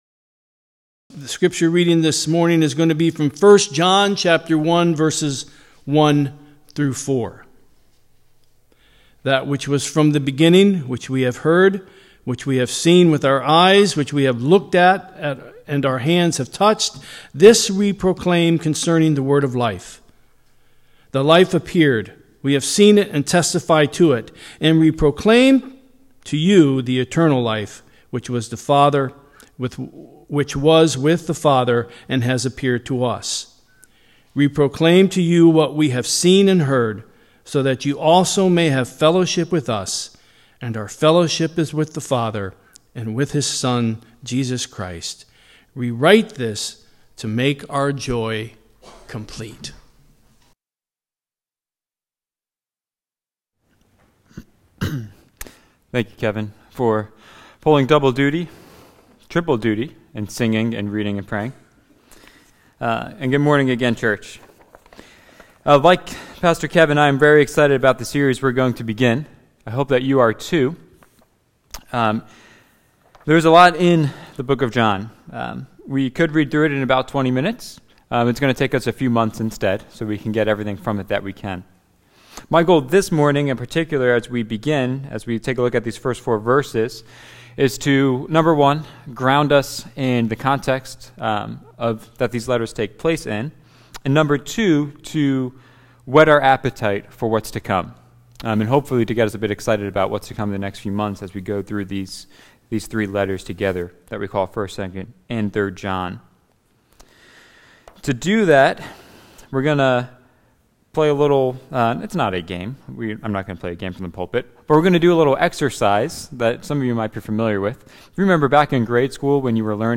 4-7-24-Sermon-Final.m4a